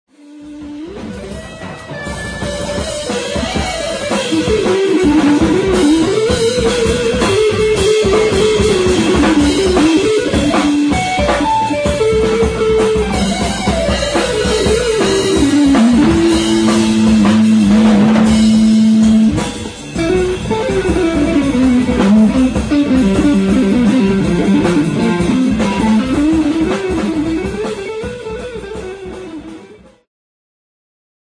Segment Jazz-Rock
Progressive
Ambient
Instulmental